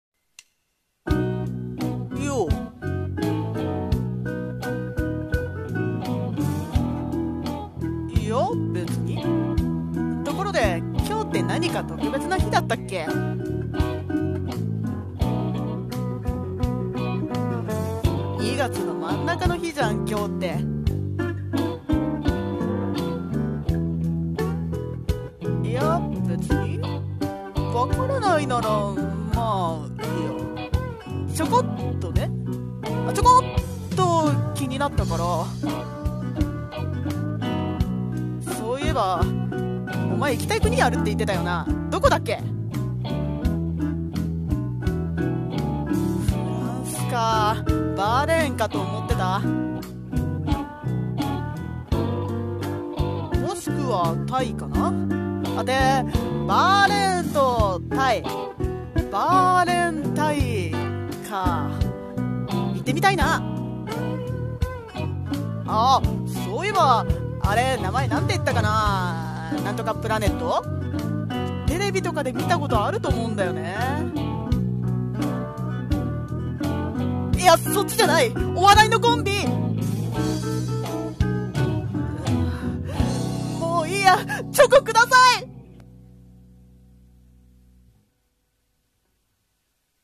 声劇【バレンタイン】※バレンタイン台本